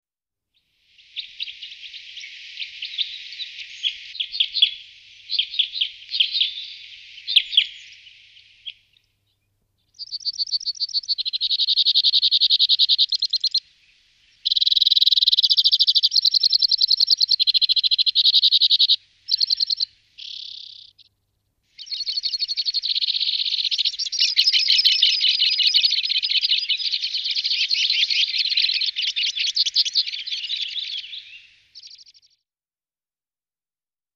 Звуки пения клеста можно услышать в густом сосновом или еловом лесу в любое время года.
Звук белокрылого клеста:
zvuk-belokrylogo-klesta.mp3